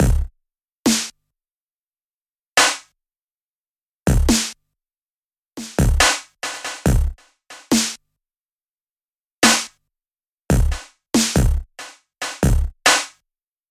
描述：在他们进来的纸板箱里摇动热玉米粉蒸丸糖果。 用Tascam DR40录制。